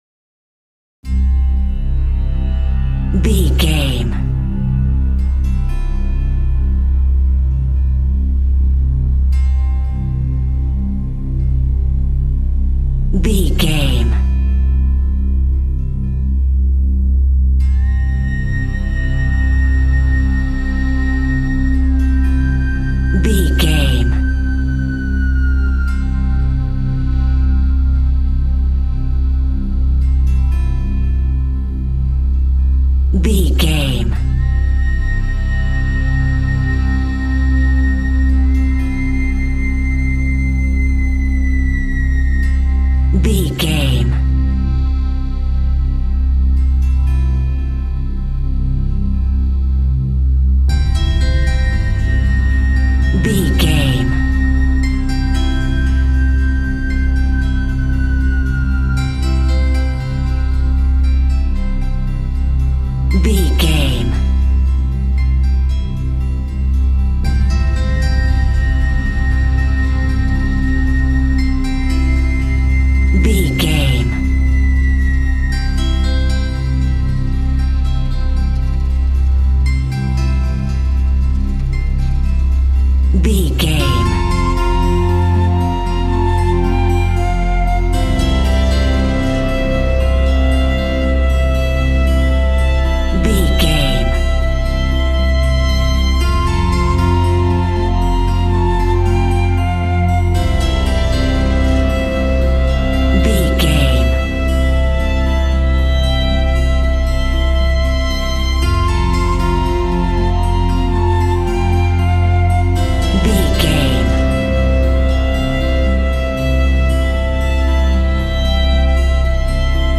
Ionian/Major
Slow
relaxed
drum machine
synthesiser